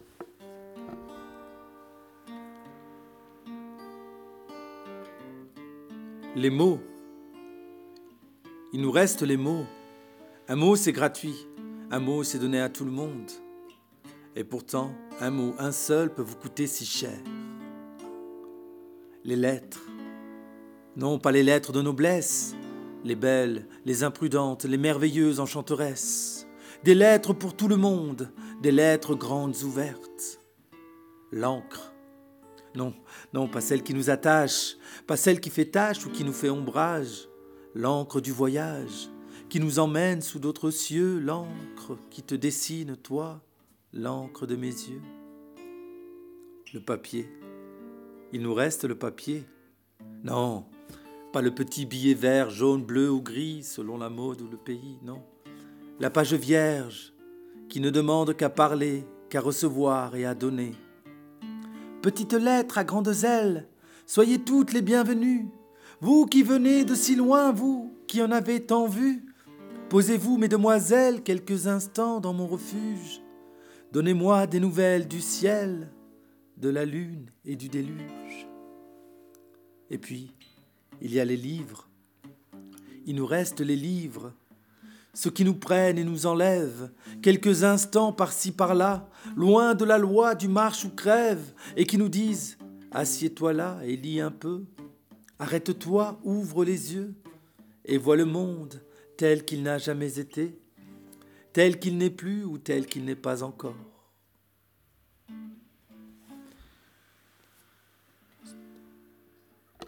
Extrait du roman d'HK "Le coeur à l'outrage" (Riveneuve, 2017) lu par l'auteur Kaddour Hadadi, accompagné à la guitarre